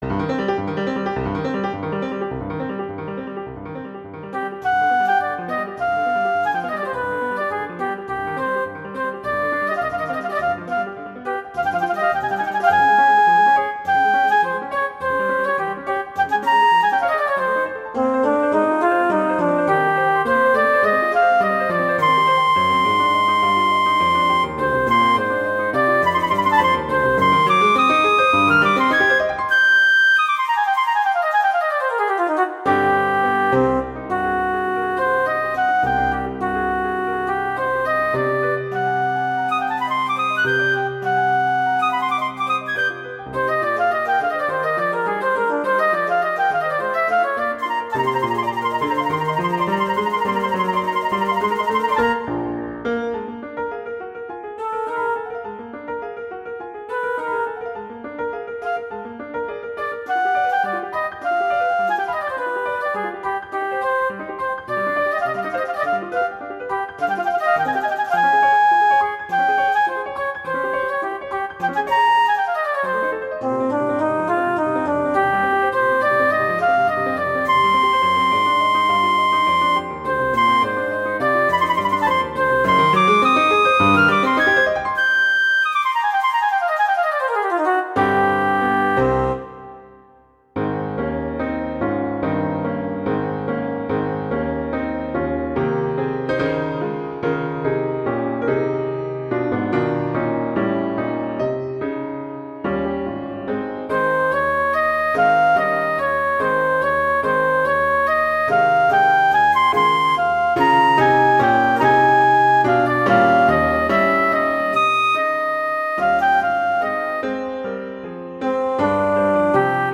flute and piano
classical, french